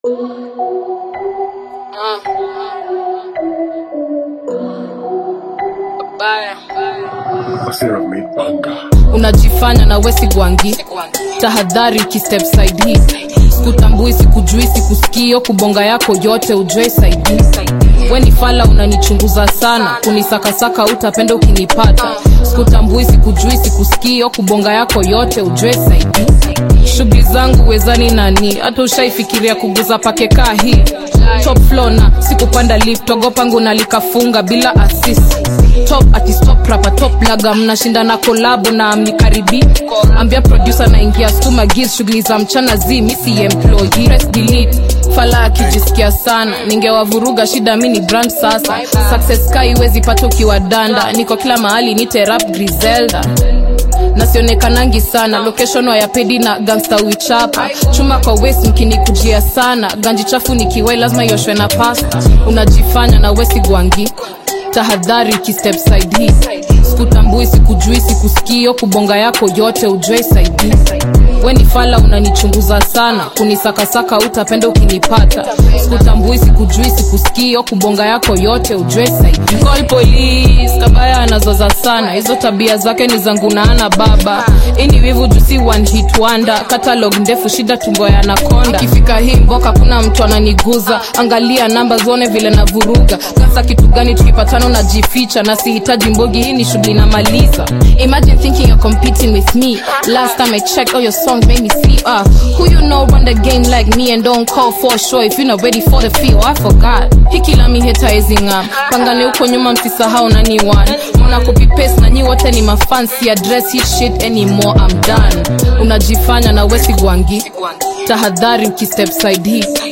vibrant Gengetone/Afro-Pop single
Kenyan rapper and singer
energetic delivery and modern East African club sound